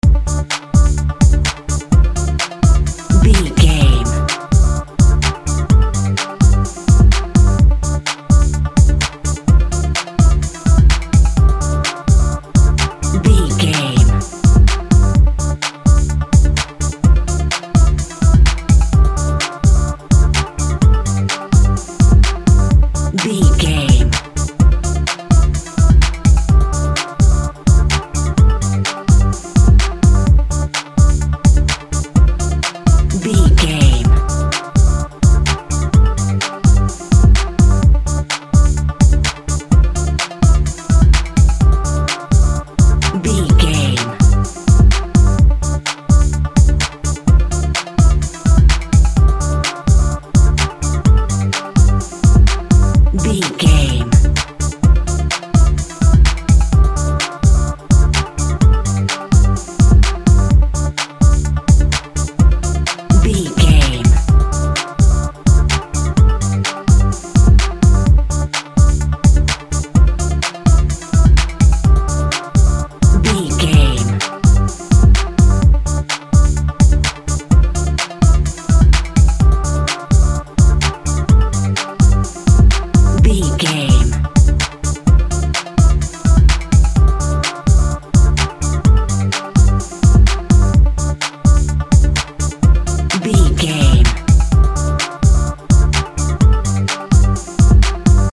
Electro Spaceship Music.
Fast paced
Aeolian/Minor
D
funky
uplifting
futuristic
energetic
synthesiser
drum machine
Drum and bass
break beat
sub bass
synth lead